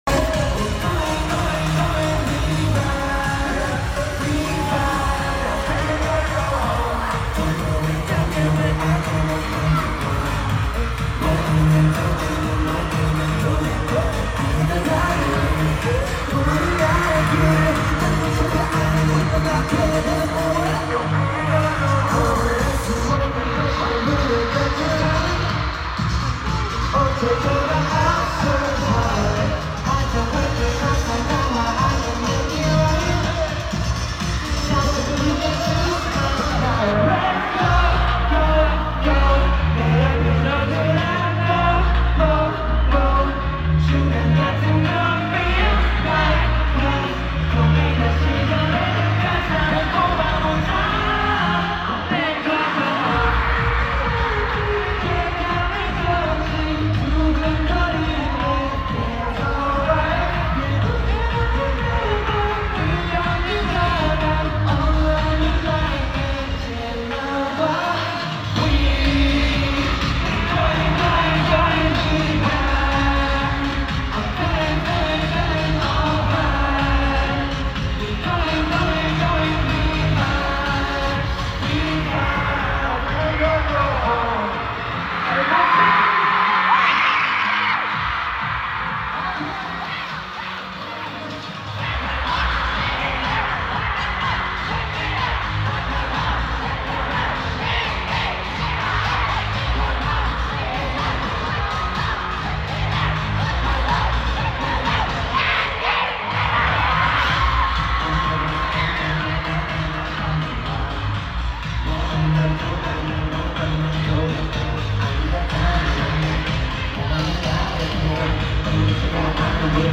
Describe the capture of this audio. from the Concert in New York Day 1 Belmont Park at the UBS Arena Stadium!